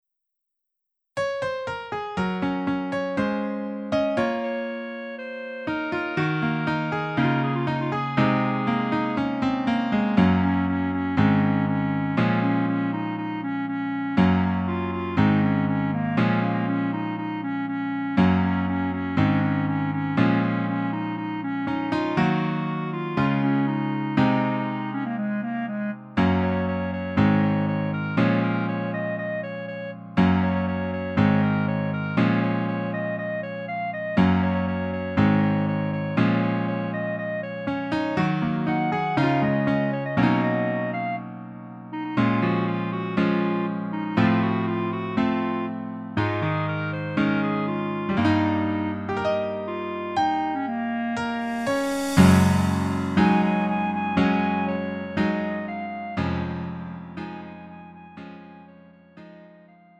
음정 -1키 4:00
장르 구분 Lite MR